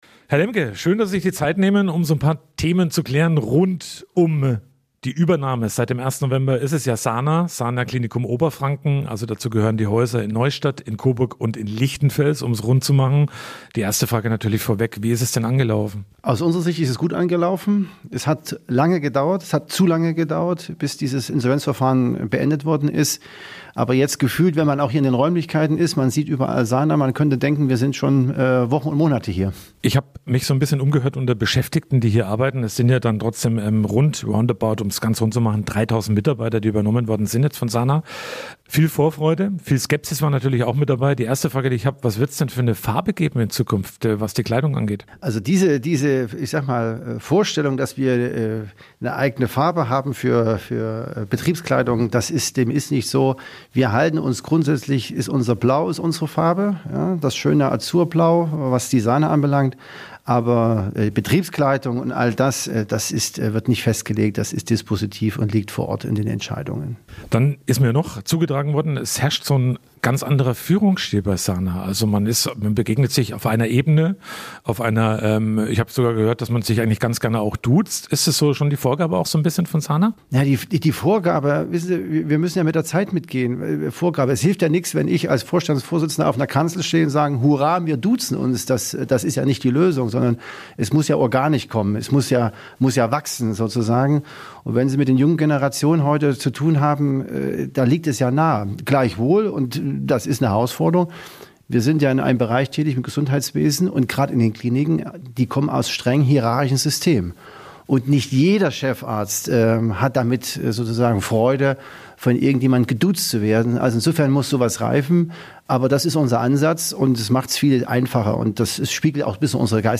Aus Regiomed wird Sana - Interview